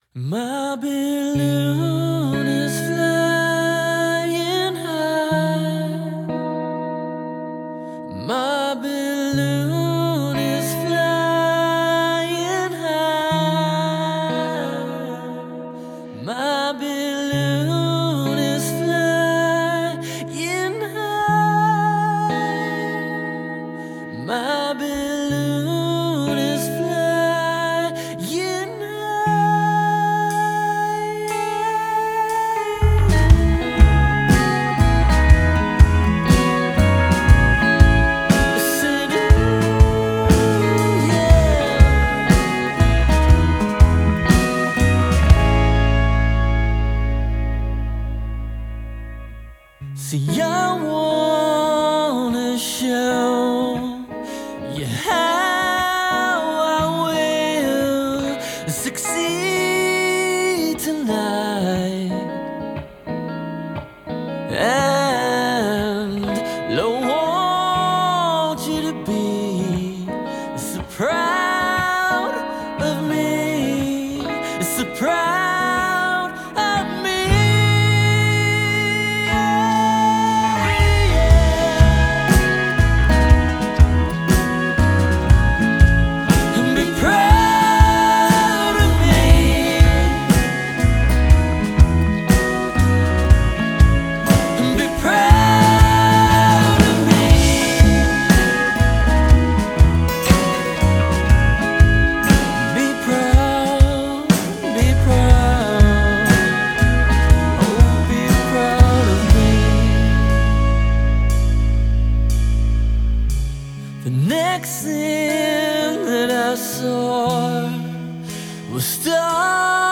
• Genre: Rock / Indie